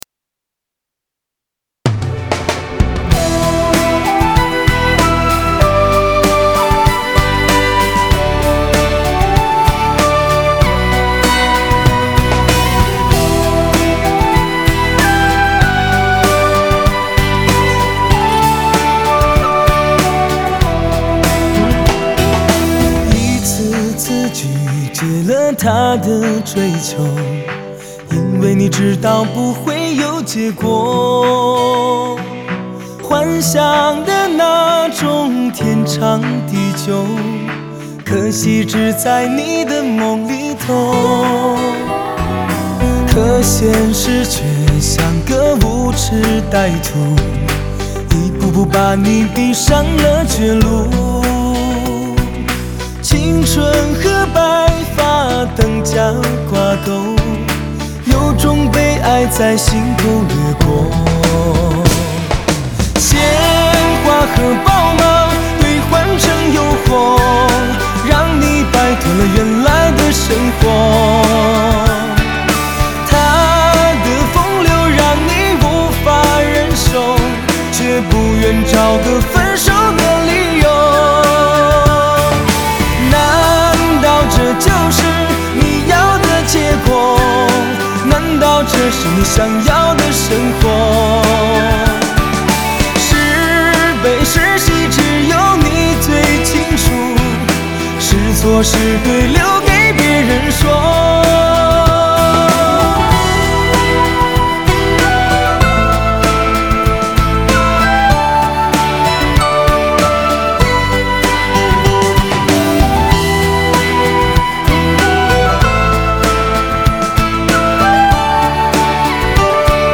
类别: 流行